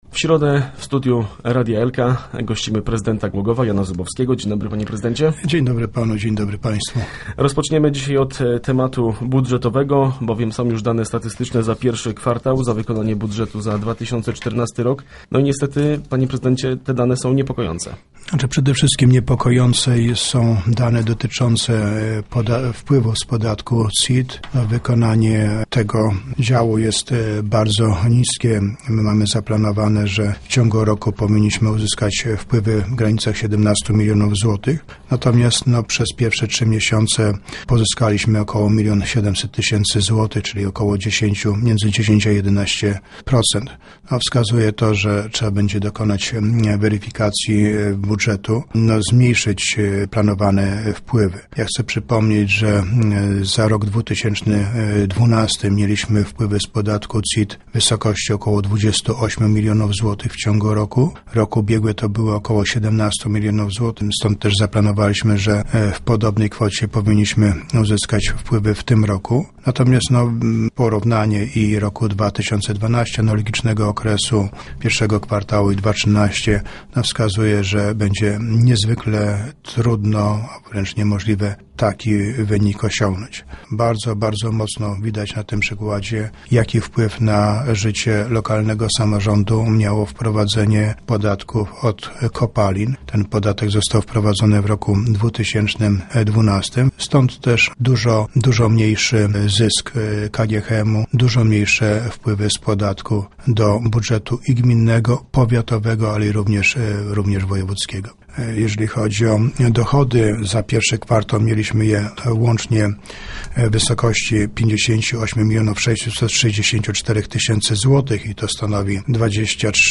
Władze Głogowa zaniepokojone są niskimi wpływami z tytułu podatku CIT. Prezydent Jan Zubowski był gościem środowych Rozmów Elki.